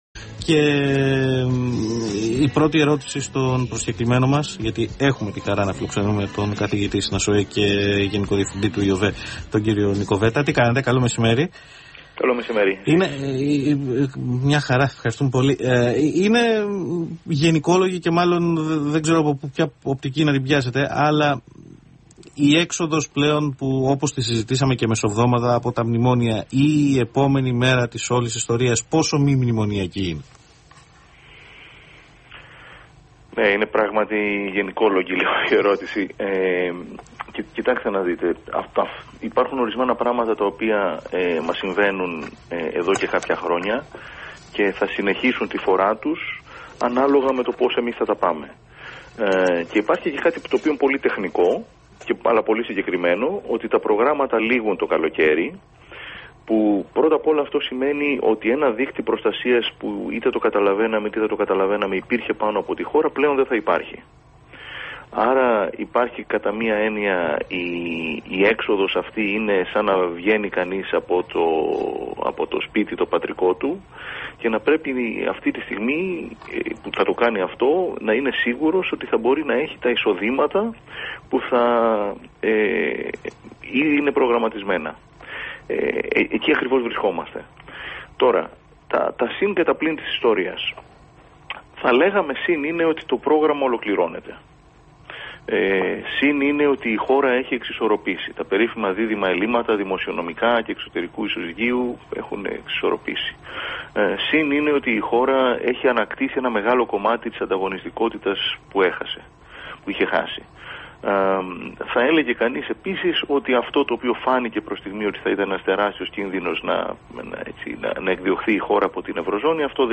Συνέντευξη
Δημοσιότητα Συνέντευξη στον Αθήνα 9,84 Ημερομηνία Δημοσίευσης